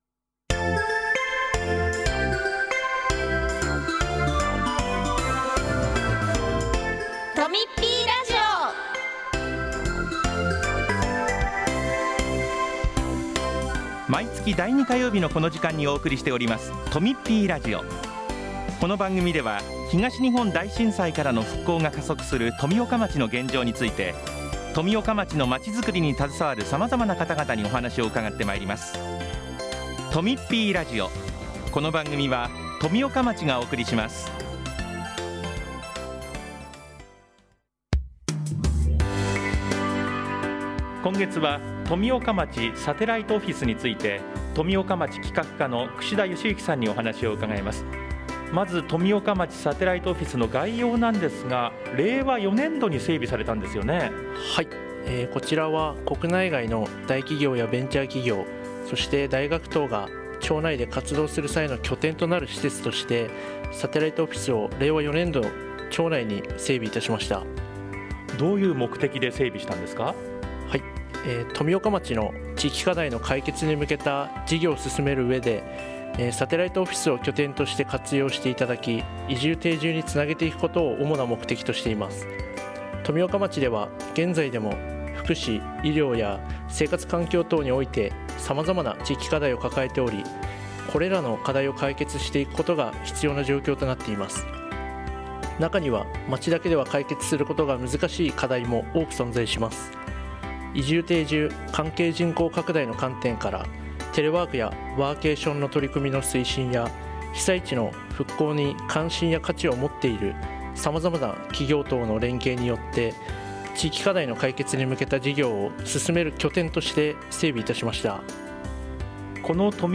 8月8日（火曜日）に放送した「とみっぴーラジオ」を、お聴きいただけます。